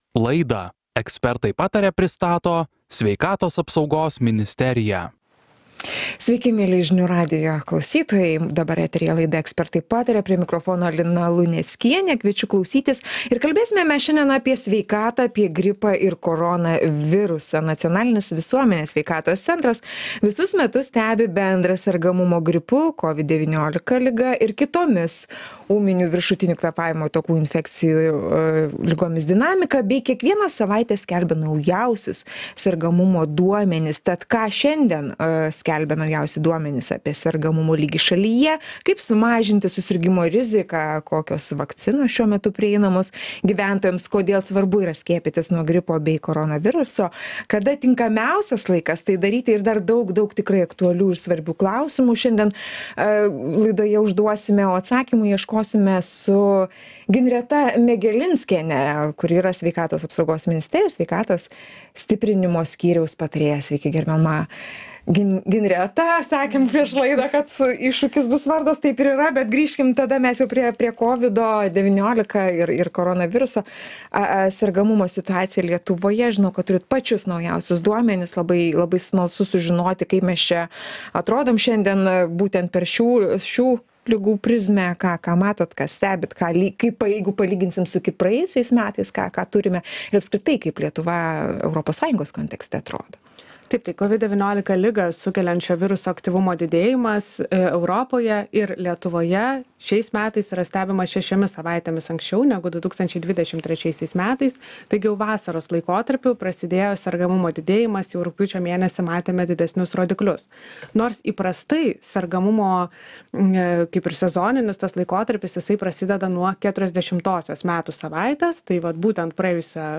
Šie ir kiti klausimai – pokalbyje